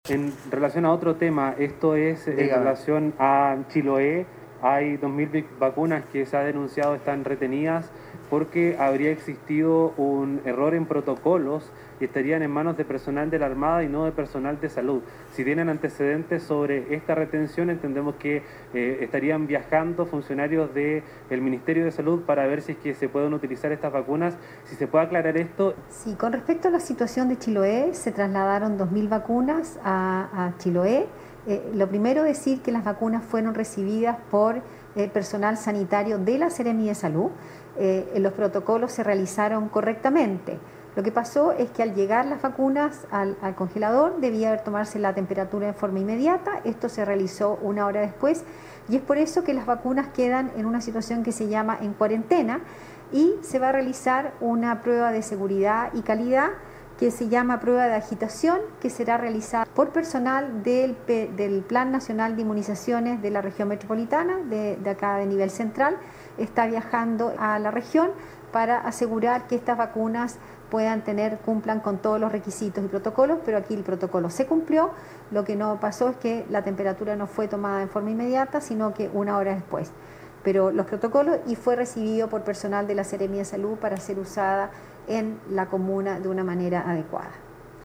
La situación planteada en Chiloé repercutió en el Palacio de la Moneda, durante el habitual informe del ministerio de Salud sobre el estado de la pandemia.
Esto fue lo consultado por el periodista en la oportunidad y la respuesta de la subsecretaria de Salud Pública, Paula Daza.